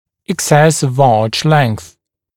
[ɪk’ses əv ɑːʧ leŋθ] [ek-] [ик’сэс ов а:ч лэнс] [эк-] избыток длины дги, избыточная длина дуги